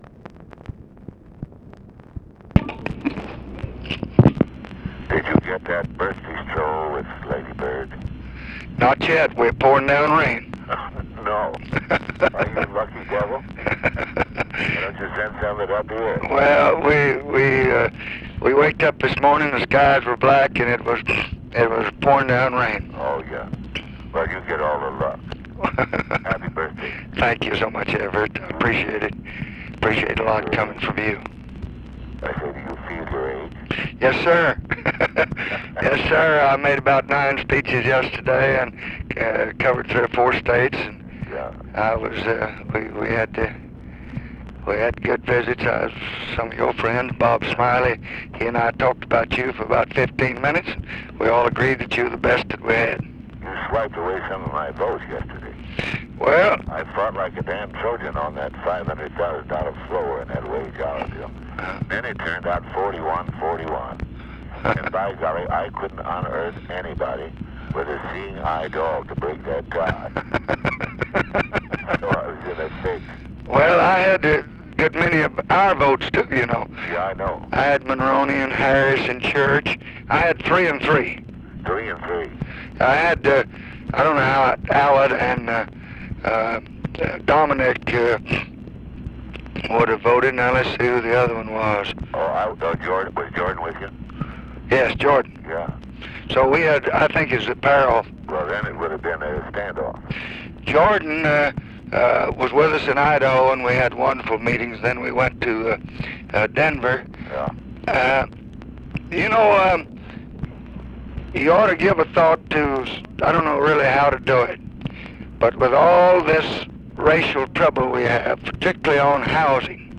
Conversation with EVERETT DIRKSEN and LADY BIRD JOHNSON, August 27, 1966
Secret White House Tapes